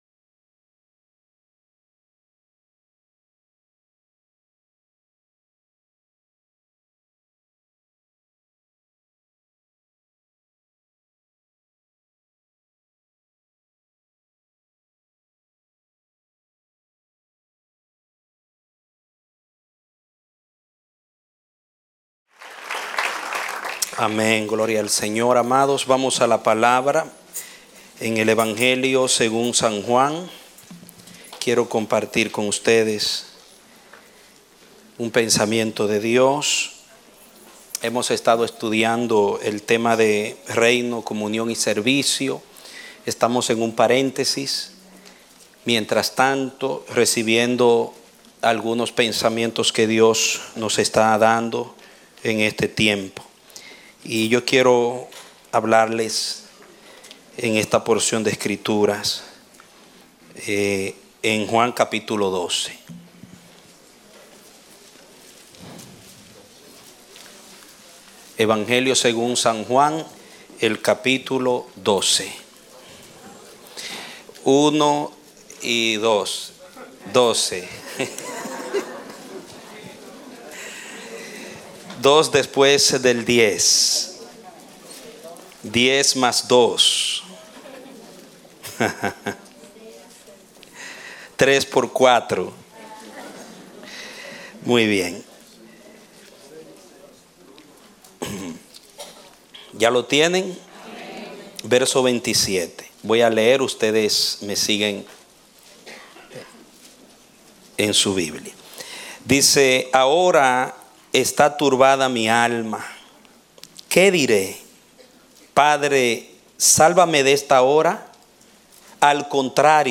Prédicas – El Amanecer de la Esperanza Ministry